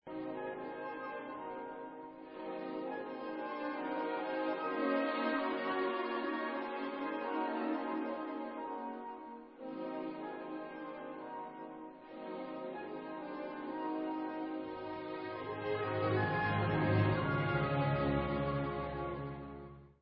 Des dur (Allegretto grazioso) /Dumka